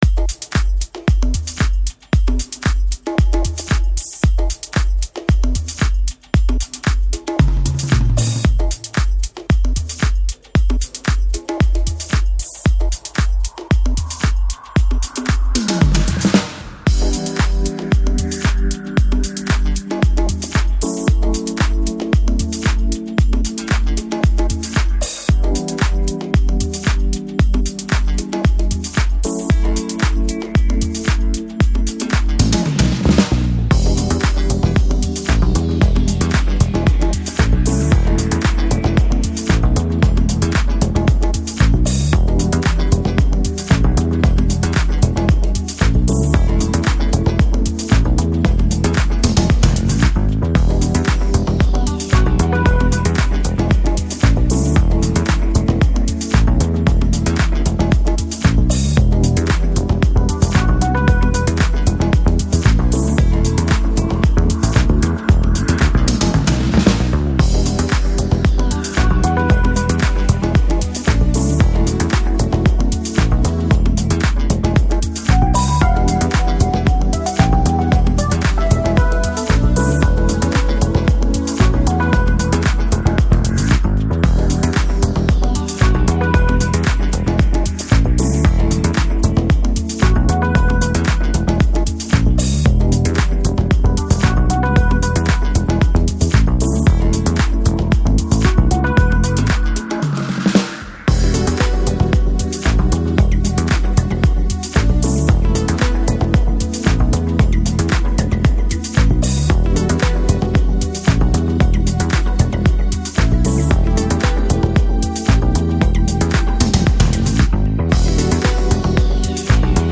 Lounge, Chillout, Chill House